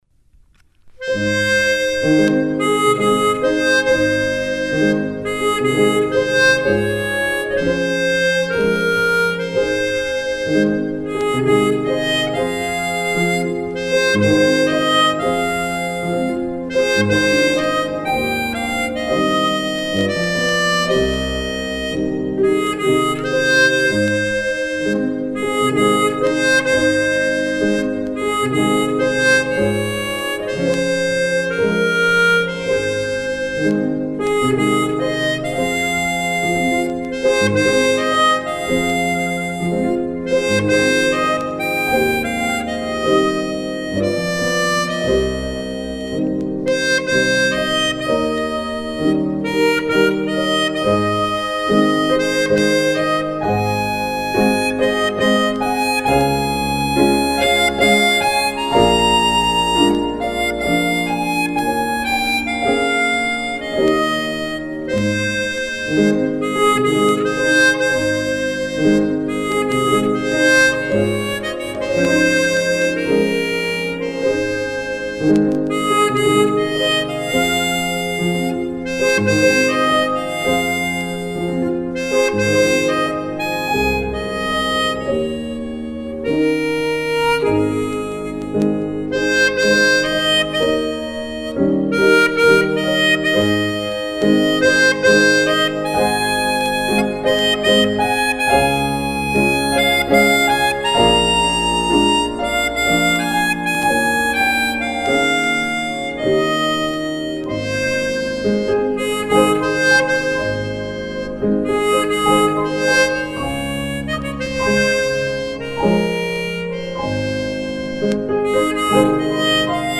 Brahms Opus 39 n°15 - Harmonica